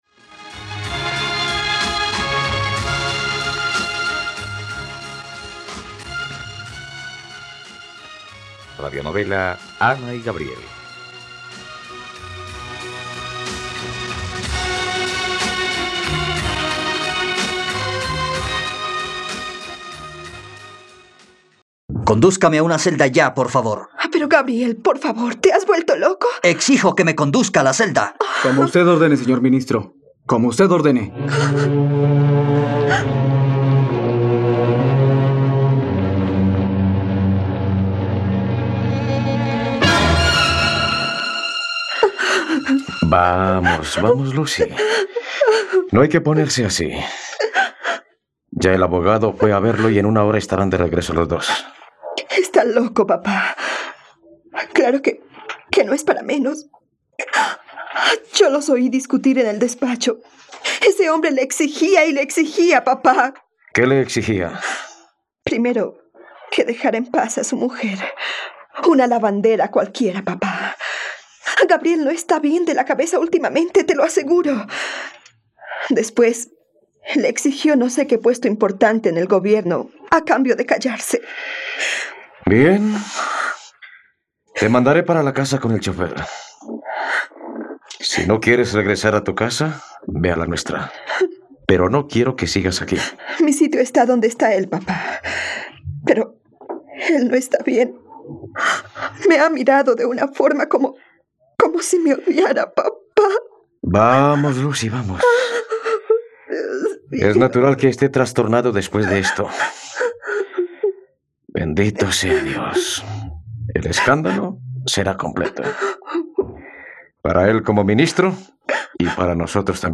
Ana y Gabriel - Radionovela, capítulo 94 | RTVCPlay